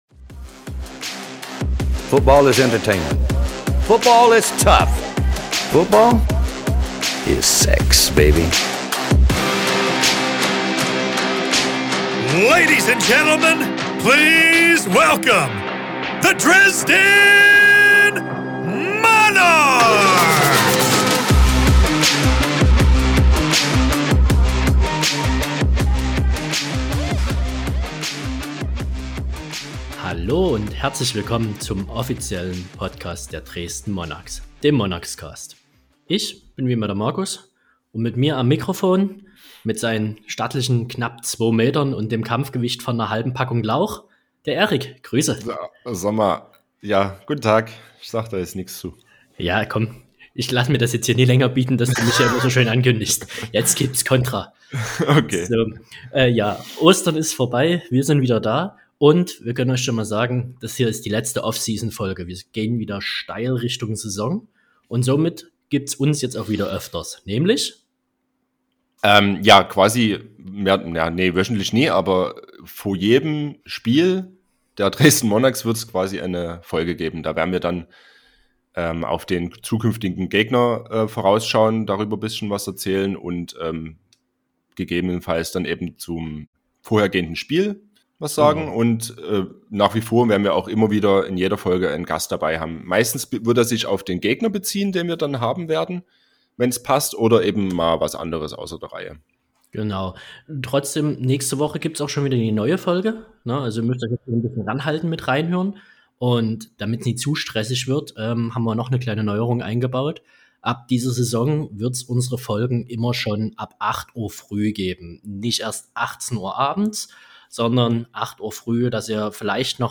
Wir informieren euch in der GFL freien Zeit einmal monatlich mit einer Folge, in welcher wir euch über aktuelle News auf dem Laufenden halten. Außerdem wird immer ein interessanter Gast in einem Interview Rede und Antwort stehen.